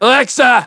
synthetic-wakewords / alexa /ovos-tts-plugin-deepponies_Trevor_en.wav
ovos-tts-plugin-deepponies_Trevor_en.wav